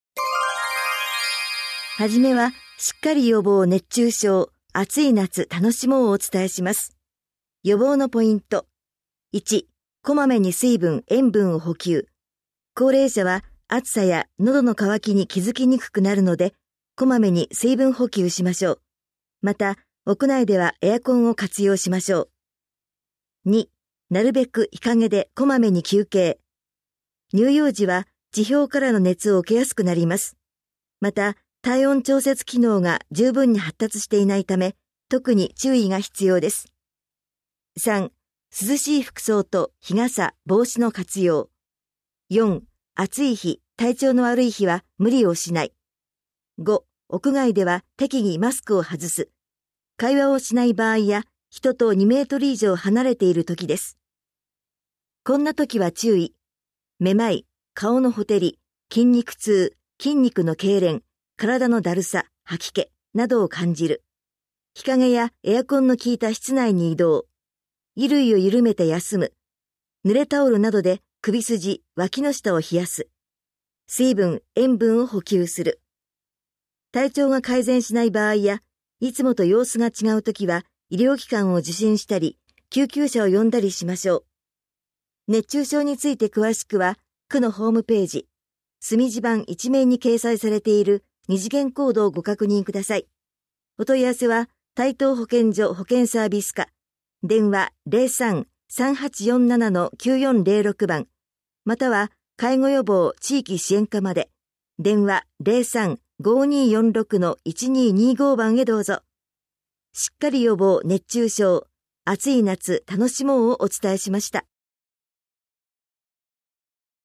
広報「たいとう」令和4年6月20日号の音声読み上げデータです。